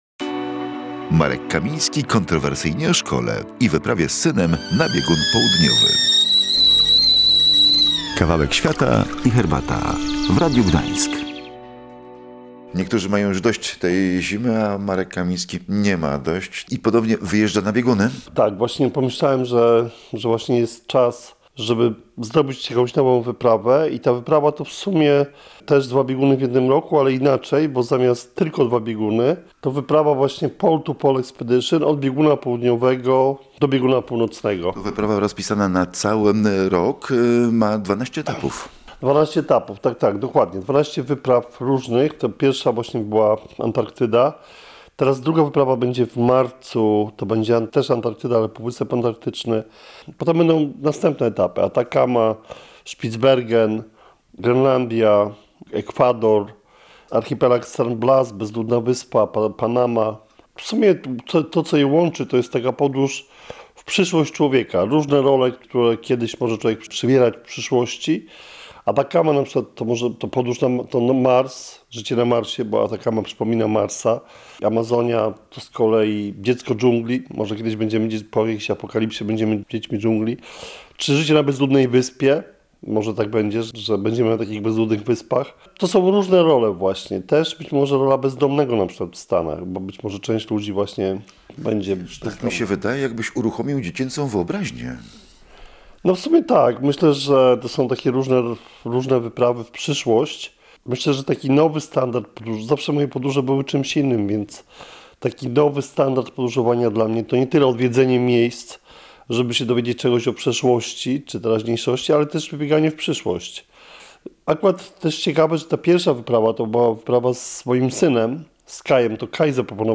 To jest rozmowa o podróży ojca z synem, o idei powrotu „na bieguny” obejmującej większy projekt podróżniczy Marka Kamińskiego. Chodzi o rozpisany na 12 etapów projekt „Pole 2 Pole”.